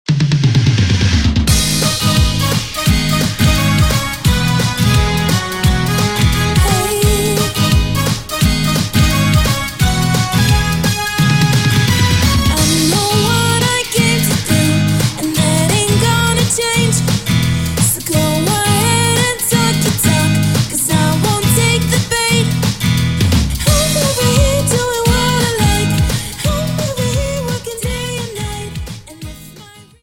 Dance: Jive 43 Song